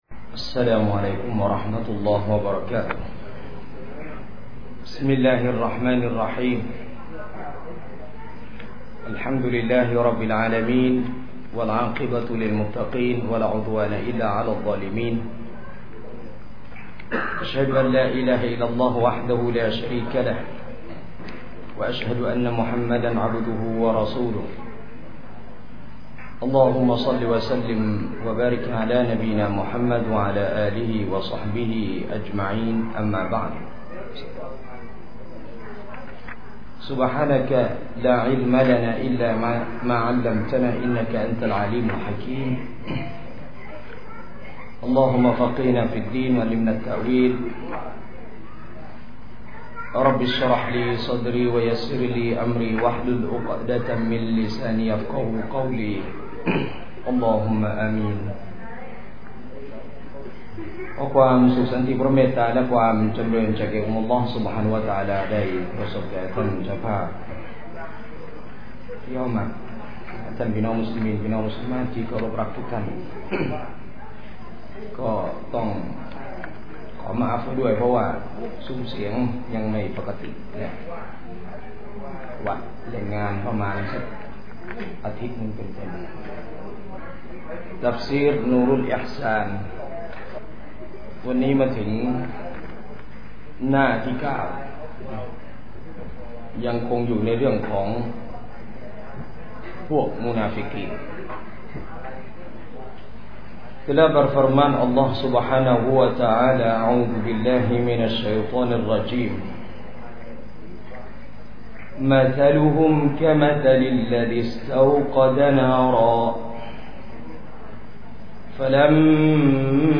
สถานที่ : บ้านพนัสนาวรรณ อ.พนัสนิคม จ.ชลบุรี